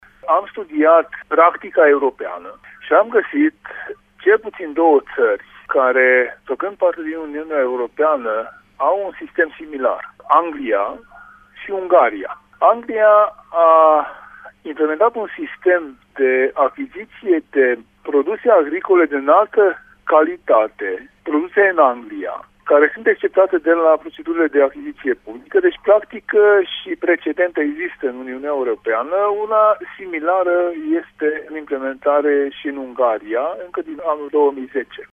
Există deja precedent la nivelul Uniunii Europene în Marea Britanie și Ungaria, a mai explicat senatorul Tanczos Barna: